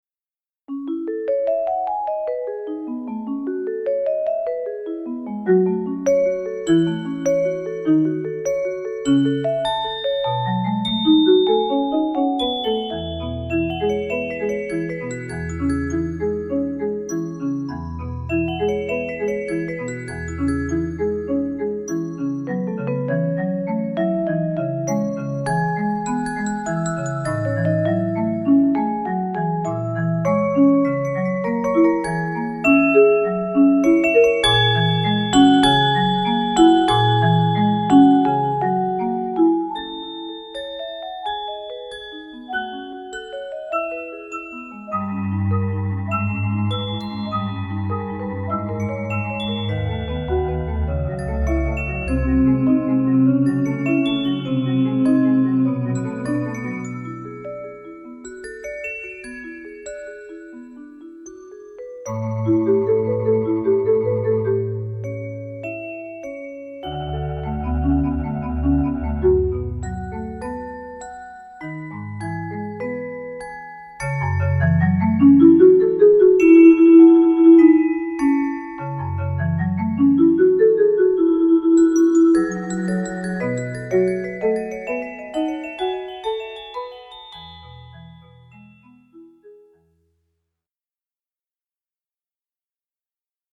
Voicing: 14 Percussion